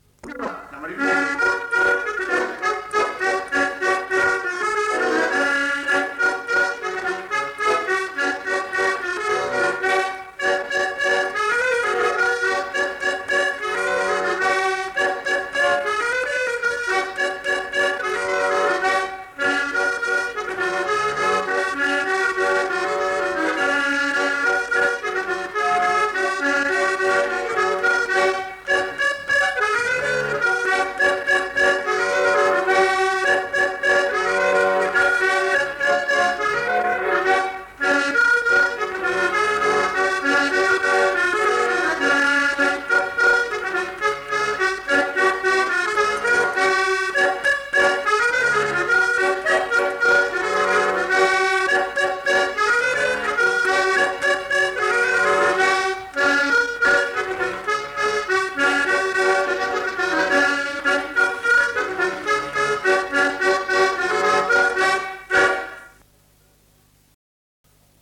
Aire culturelle : Cabardès
Lieu : Mas-Cabardès
Genre : morceau instrumental
Instrument de musique : accordéon diatonique
Danse : polka piquée